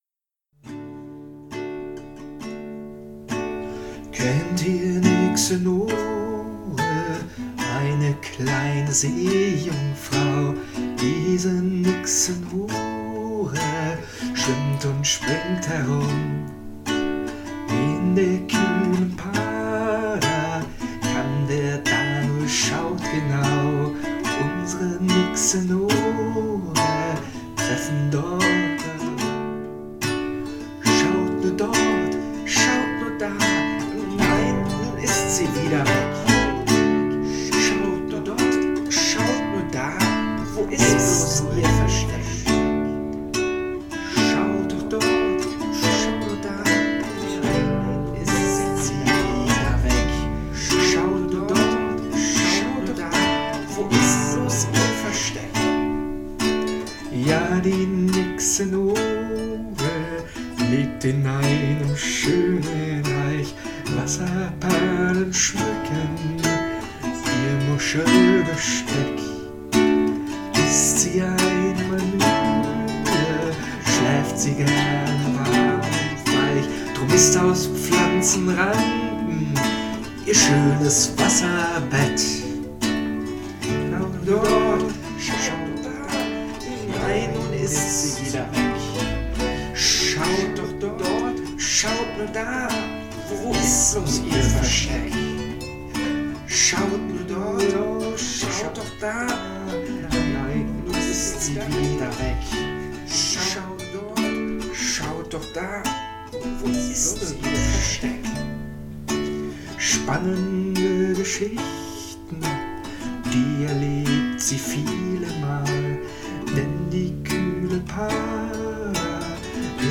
Hörspiel-Nore-mit-Geräuschen.mp3